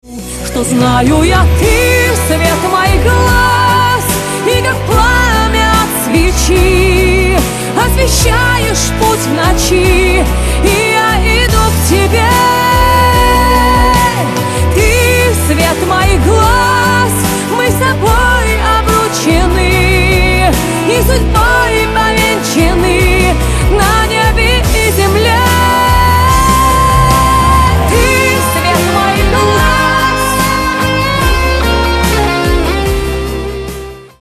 • Качество: 128, Stereo
поп
громкие
женский вокал